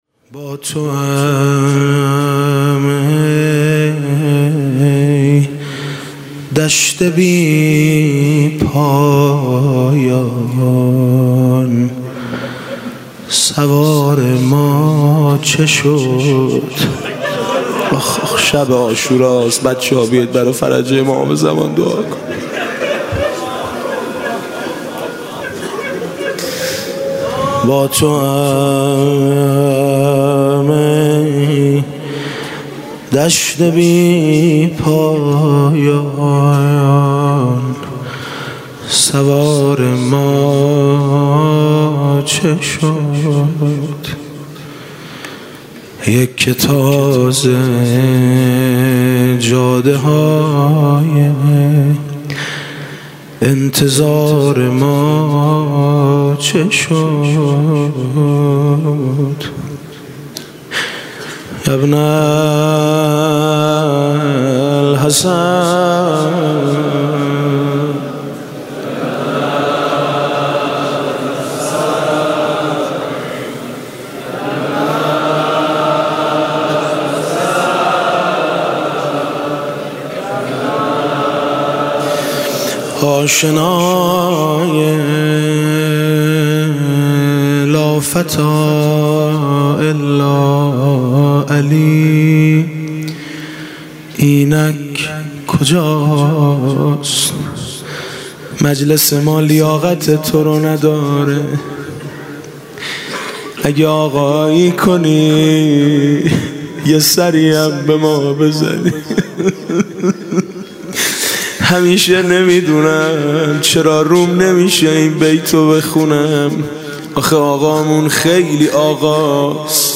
شب عاشورا محرم 96 - هیئت میثاق - مناجات با امام زمان عجل الله فرجه